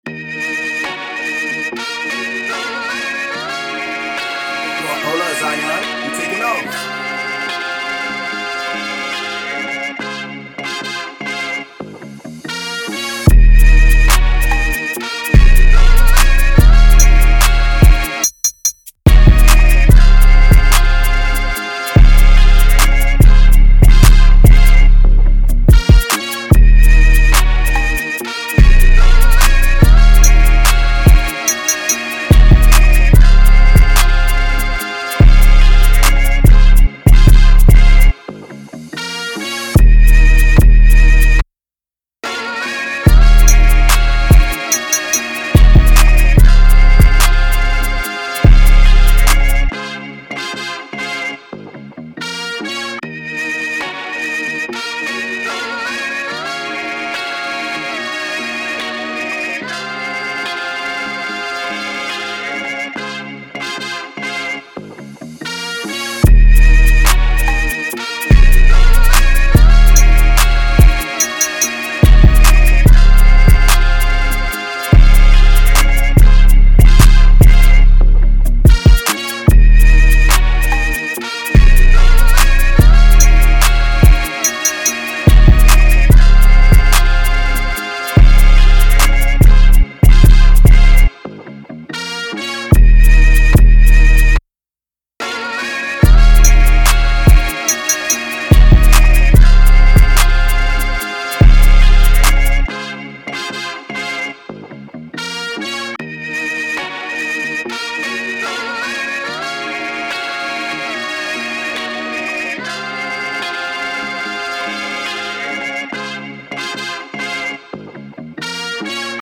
Drill
145 C# Major